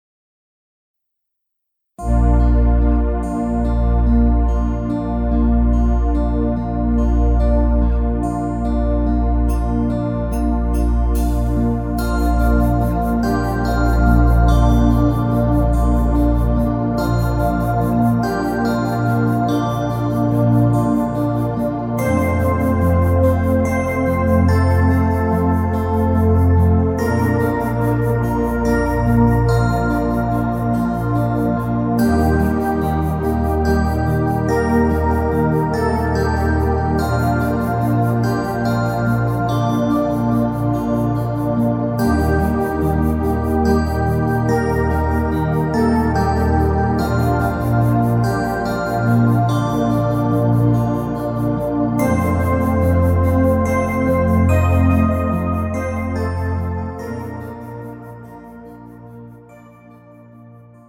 음정 Bb 키
장르 가요 구분 Pro MR